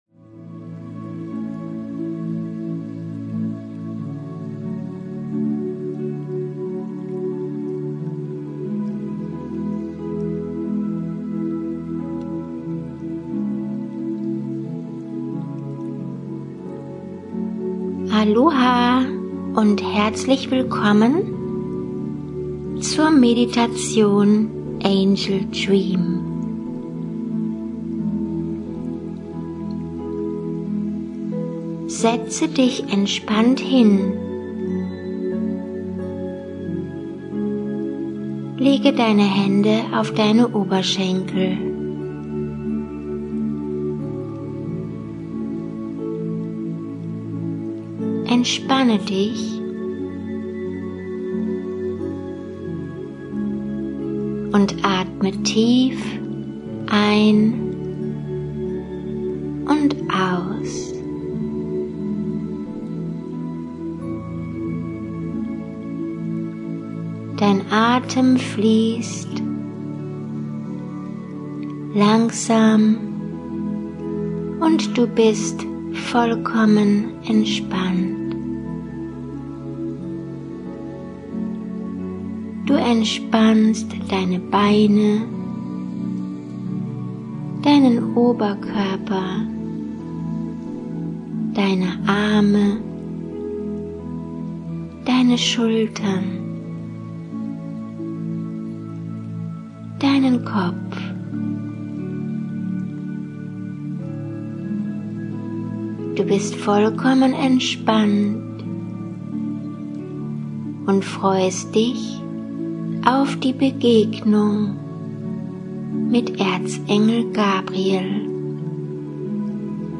Kontakt zu deinen Engeln In dieser geführten Meditation verbindest du dich mit Erz …